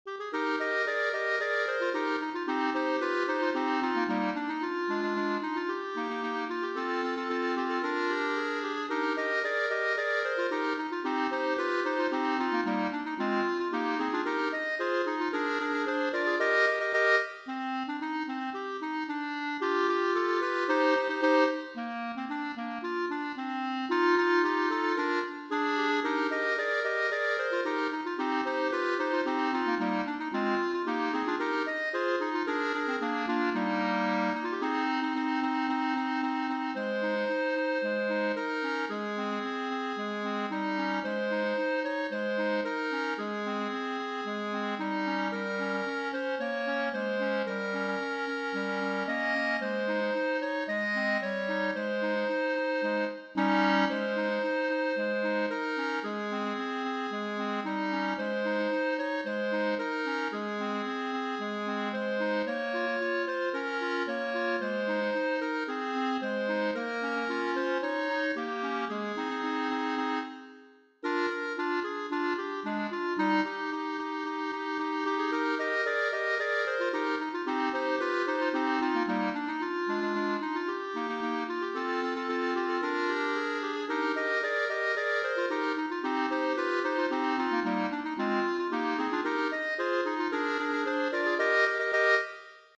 Voicing: Clarinet Trio